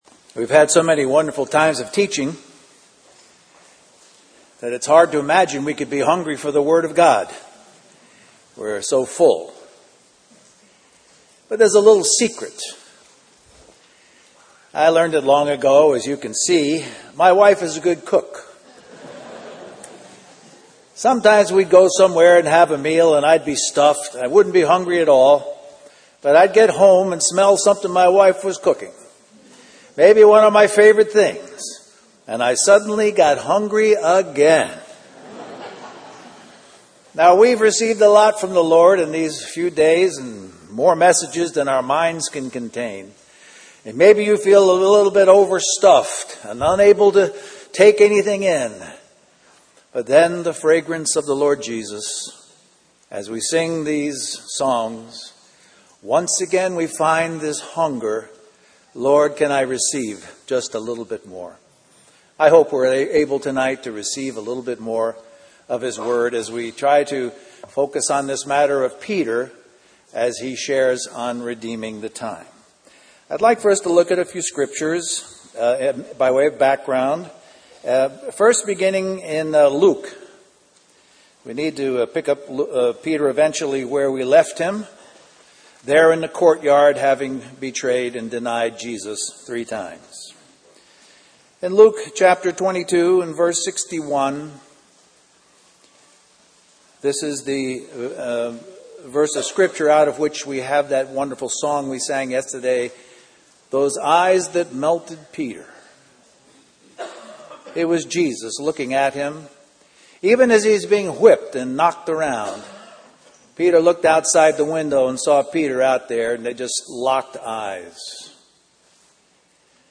2015 Harvey Cedars Conference Stream or download mp3 Scriptures Referenced Luke 22:61-63 61 And the Lord turned